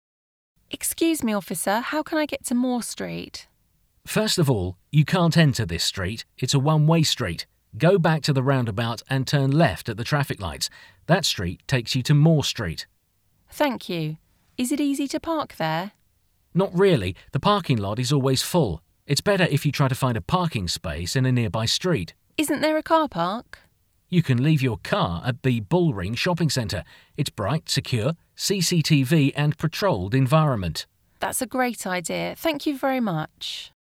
Jöjjön most egy hasznos-hangos (le is töltheted) párbeszéd városi parkolással kapcsolatban.